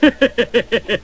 Crazy Laugh Sound Effect Free Download
Crazy Laugh